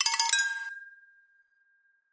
На этой странице собраны звуки, связанные с покупками и оплатой: работа кассового аппарата, сигналы терминалов, уведомления об успешной транзакции.
Звук: транзакция завершена